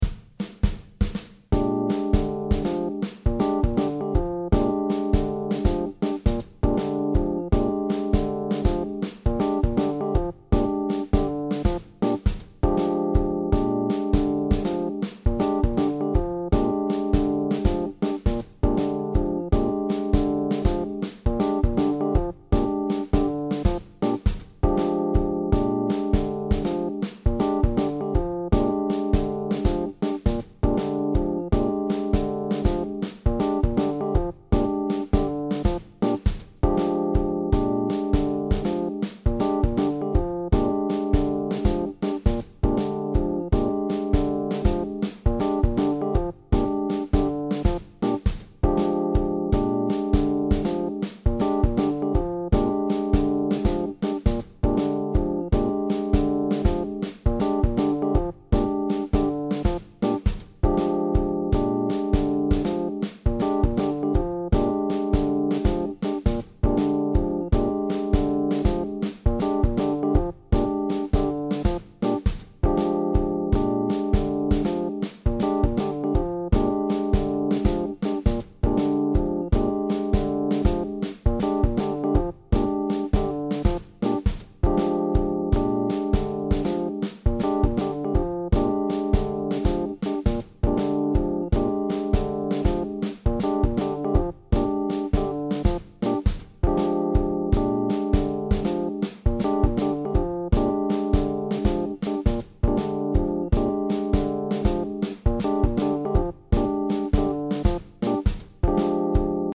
Next in Line, Mike Kamuf.
Next in Line solo comping. One bar drum intro. It’s one chord, and a two bar phrase, but I turned this into an 8-bar phrase to make it a little funner.
next-in-line-solo-comping.mp3